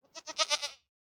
Minecraft Version Minecraft Version snapshot Latest Release | Latest Snapshot snapshot / assets / minecraft / sounds / mob / goat / idle3.ogg Compare With Compare With Latest Release | Latest Snapshot